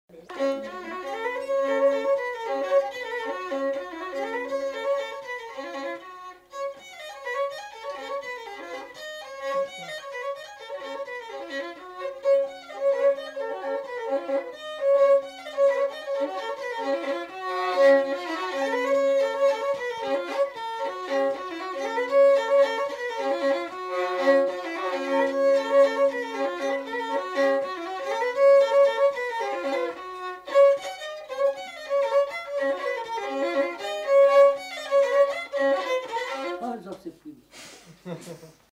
Bourrée
Aire culturelle : Limousin
Lieu : Lacombe (lieu-dit)
Genre : morceau instrumental
Instrument de musique : violon
Danse : bourrée
Notes consultables : Quelques bribes de chant en début de séquence.